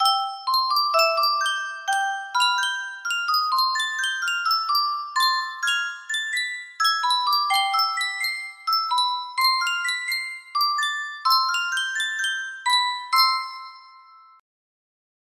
Yunsheng Custom Tune Music Box - Unknown Title music box melody
Full range 60